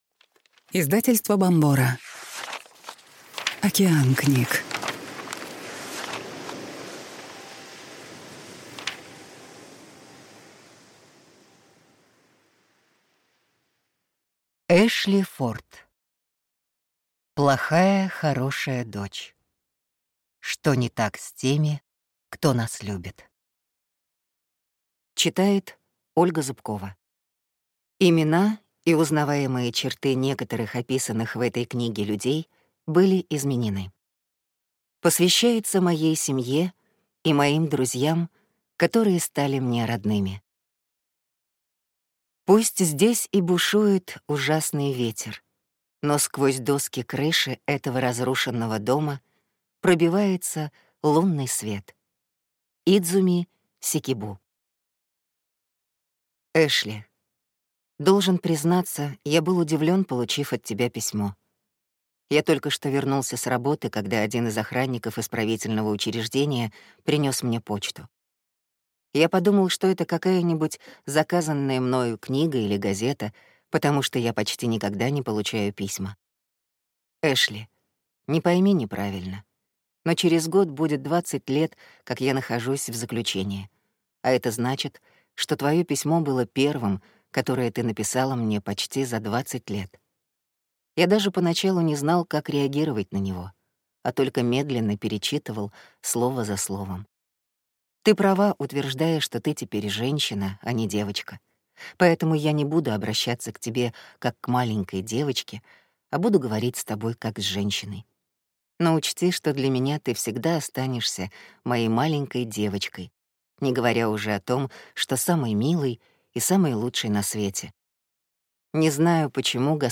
Аудиокнига Плохая хорошая дочь. Что не так с теми, кто нас любит | Библиотека аудиокниг